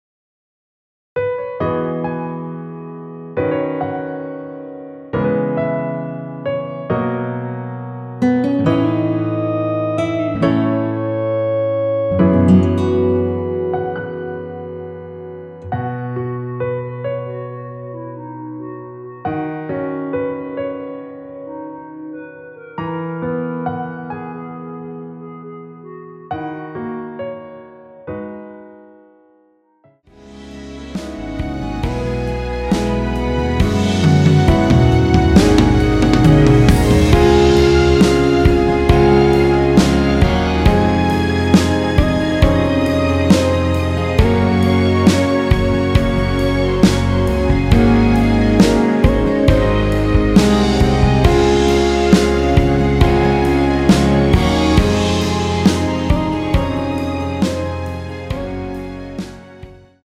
원키에서(-1)내린 멜로디 포함된 MR입니다.
앞부분30초, 뒷부분30초씩 편집해서 올려 드리고 있습니다.